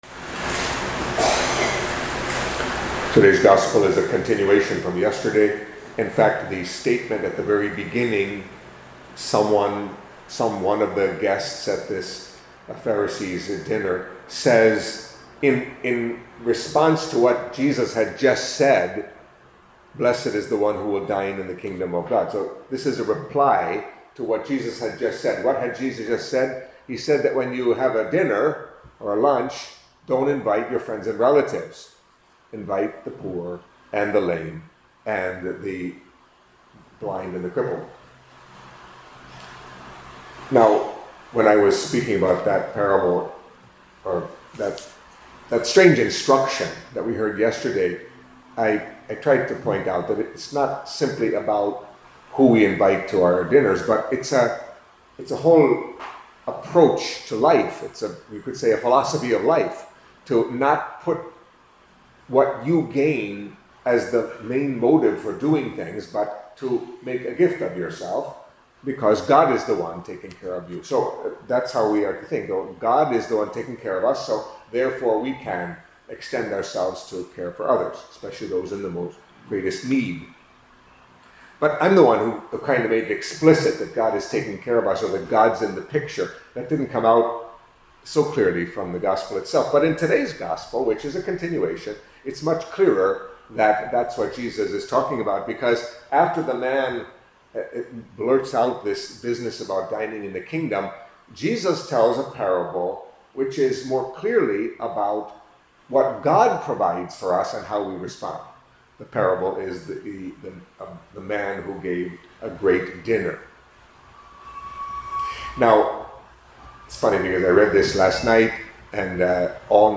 Catholic Mass homily for Tuesday of the Thirty-First Week in Ordinary Time